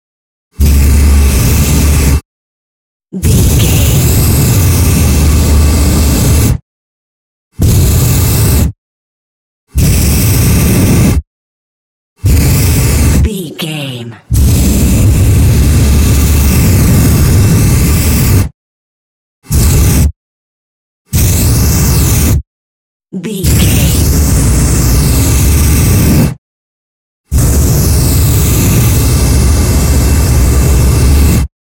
Flame thrower fire weapon
Sound Effects
industrial
mechanical